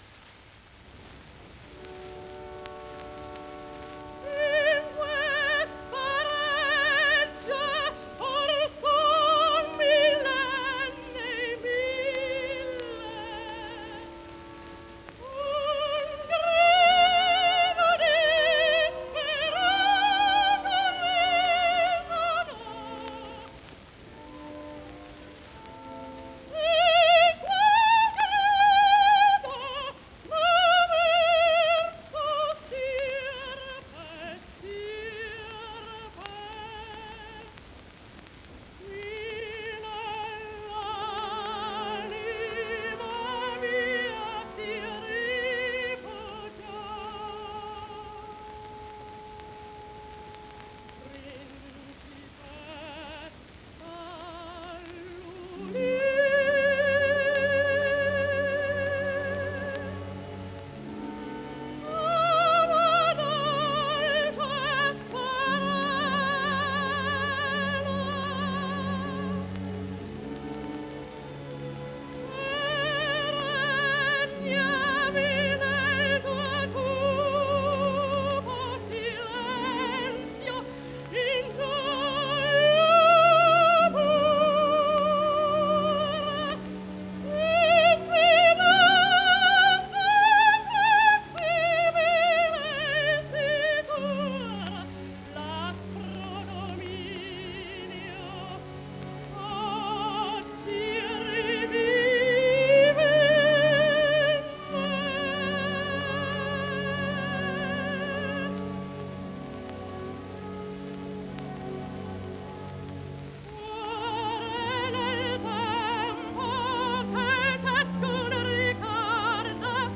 il primo brano da Turandot “In questa reggia” incisione elettrica del 19 luglio1928.